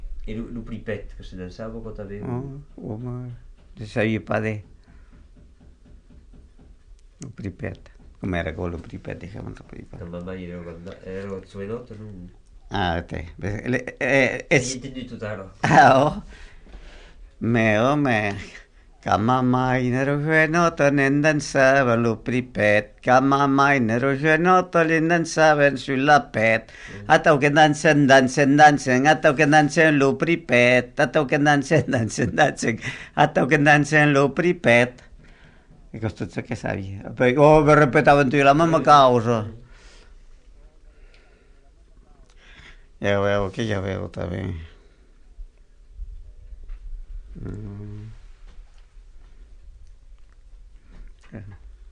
Aire culturelle : Marmandais gascon
Lieu : Damazan (canton)
Genre : chant
Effectif : 1
Type de voix : voix d'homme
Production du son : chanté
Danse : pripet